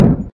木头上的脚步声
描述：有人转身走在木地板上。
标签： 地板 脚步声 木材
声道立体声